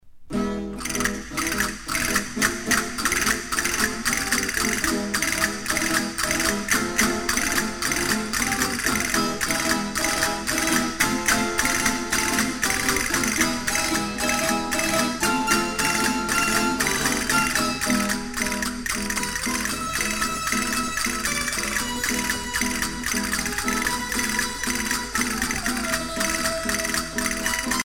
danse : paso-doble
Pièce musicale éditée